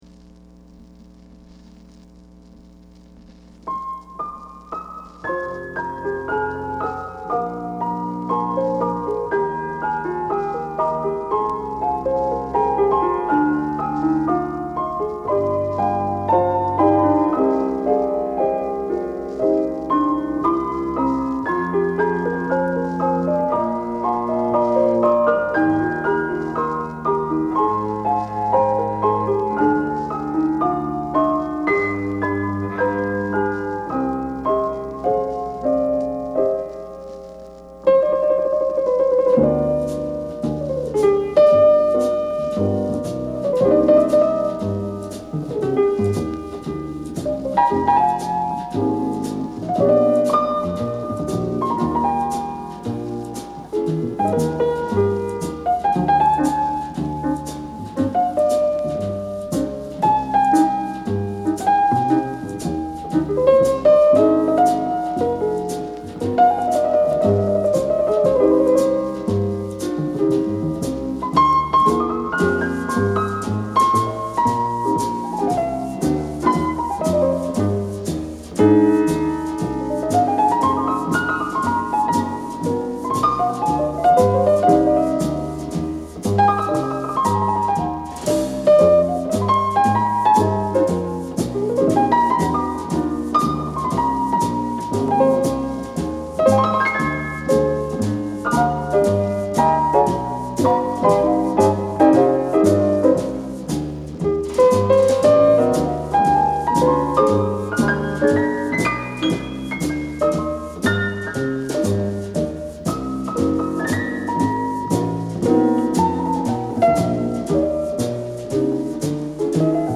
Jazz Piano Jacket
速く正確な指から出てくるのは、どこか親しみやすい質感のスウィング。
＊＊＊B-2に軽微な傷あり（試聴にてご確認ください）＊＊＊ 画像クリックで試聴出来ます。
シューマンのような抑制と、ハーレムのスウィングが同居する。